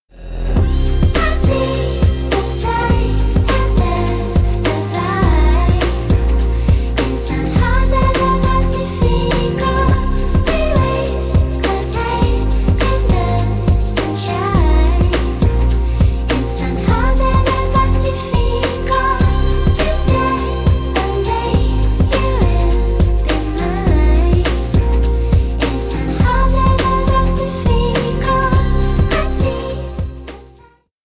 Hypnotic, ethereal indie pop with a 90s trip-hop vibe